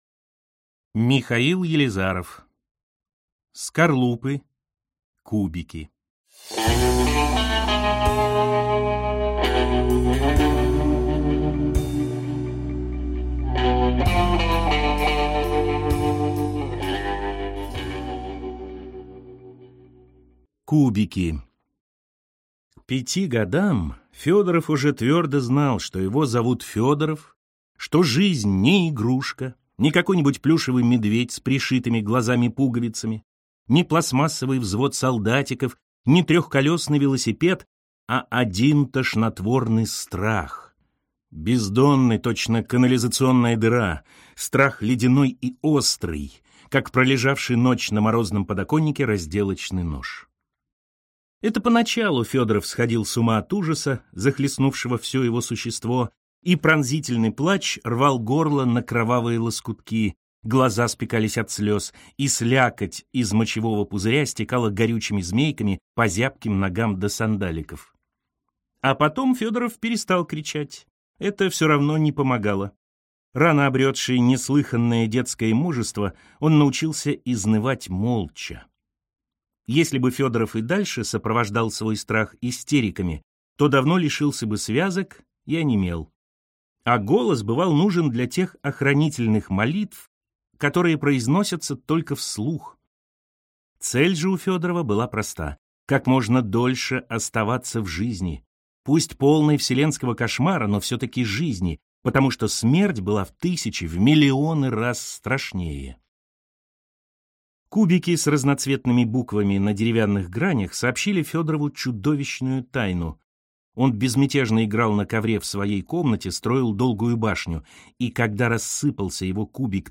Аудиокнига Скорлупы. Кубики | Библиотека аудиокниг